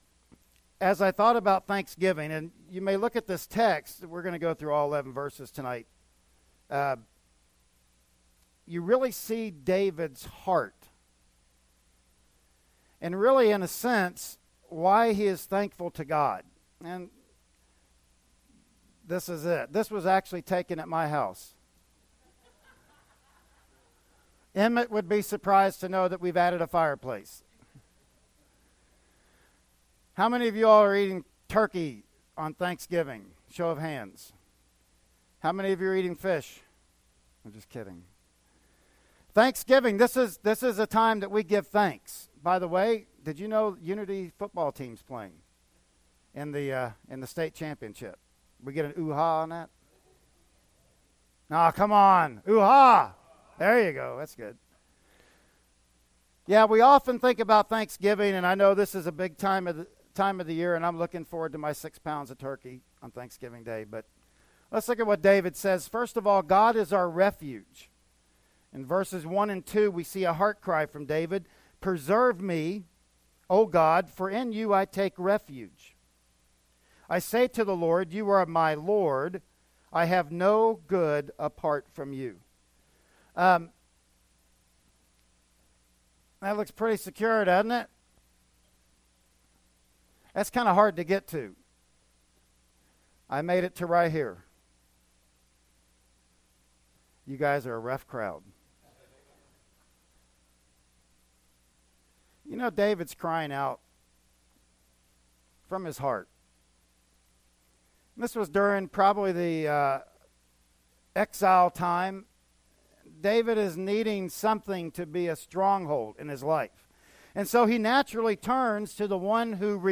Service Type: Special event